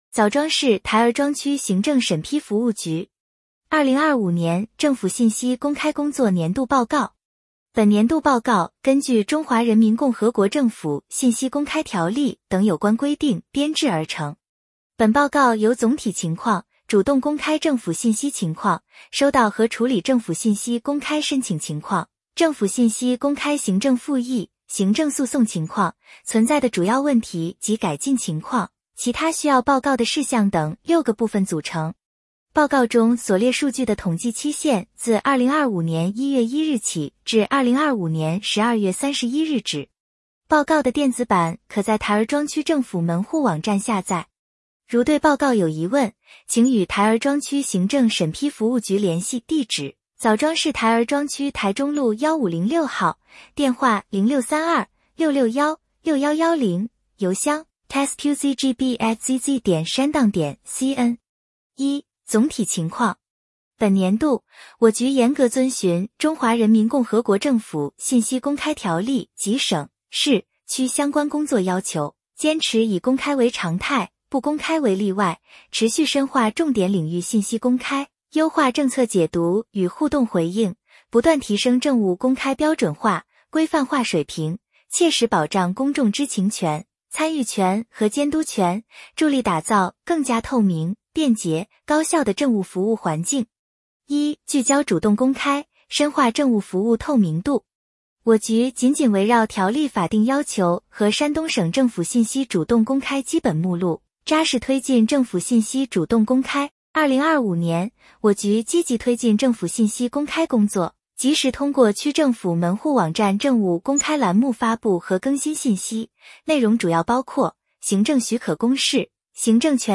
点击接收年报语音朗读 枣庄市台儿庄区行政审批服务局2025年政府信息公开工作年度报告 作者： 来自： 时间：2026-01-14 枣庄市台儿庄区行政审批服务局 2025年政府信息公开工作年度报告 本年度报告根据《中华人民共和国政府信息公开条例》等有关规定编制而成。